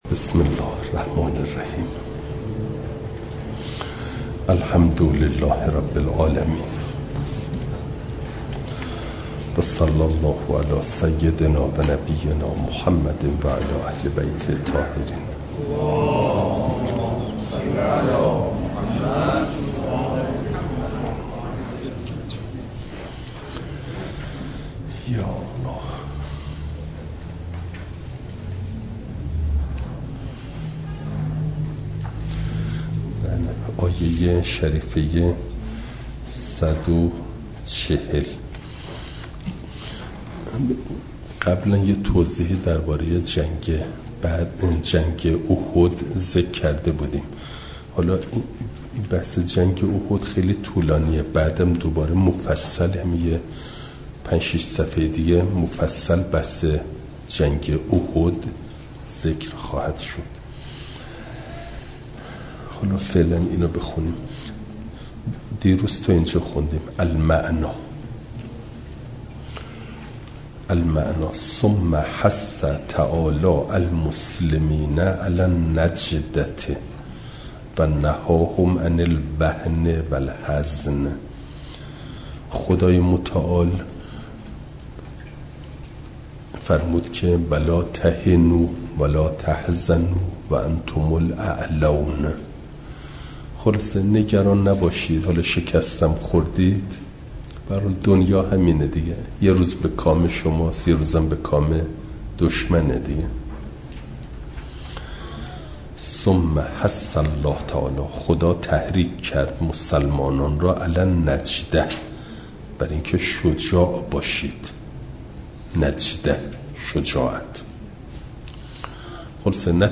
جلسه سیصد و دهم درس تفسیر مجمع البیان